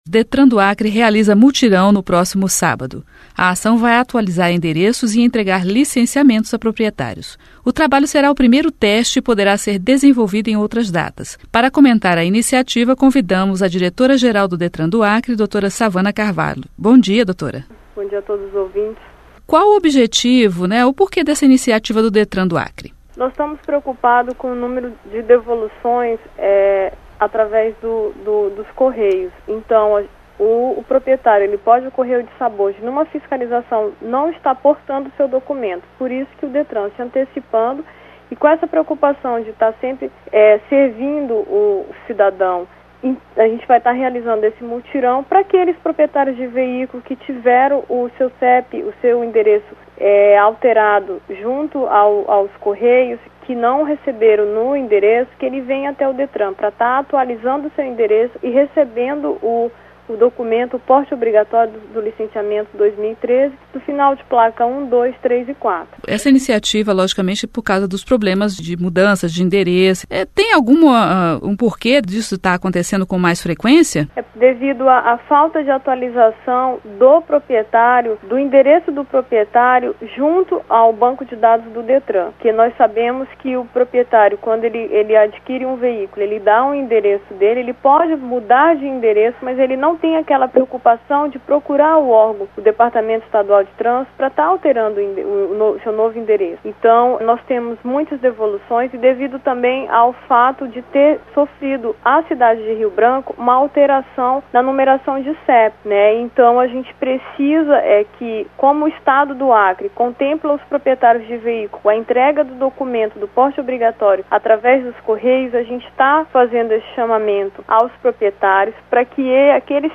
Entrevista: Detran do Acre faz mutirão para entregar 10 mil documentos